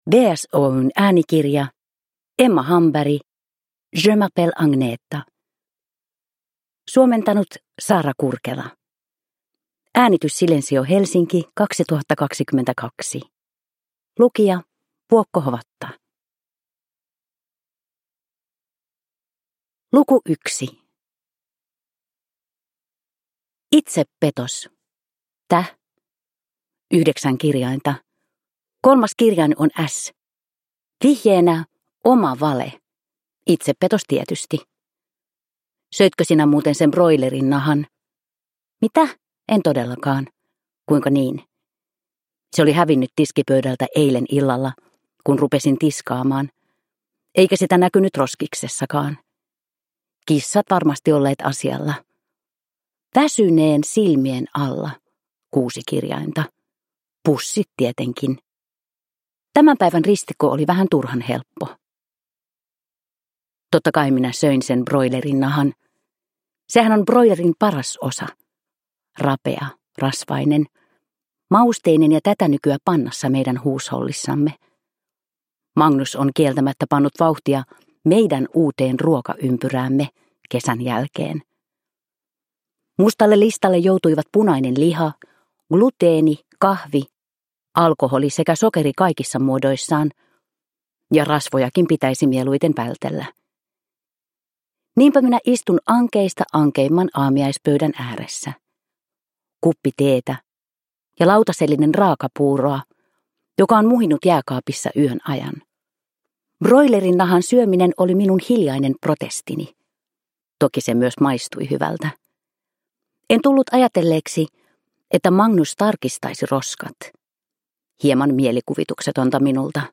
Je m'appelle Agneta (ljudbok) av Emma Hamberg | Bokon